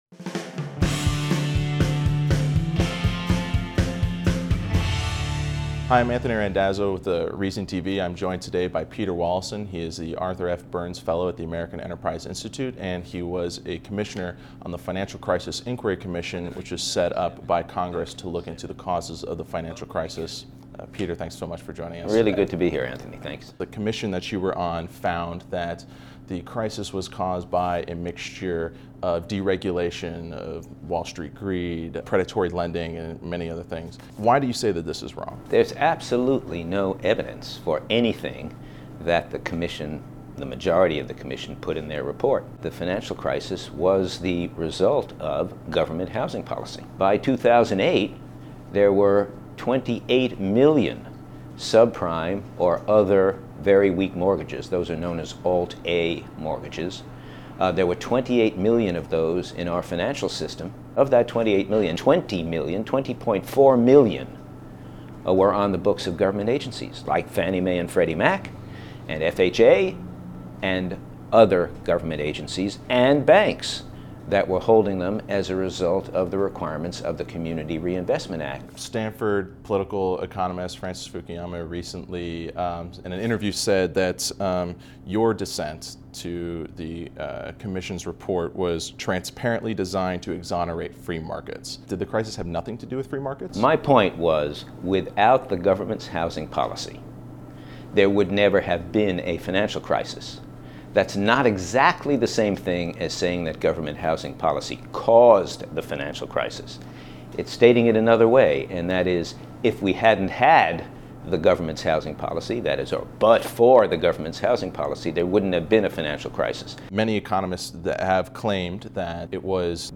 This interview was excerpted from a much-longer conversation, a transcript of which can be found here, here, and here.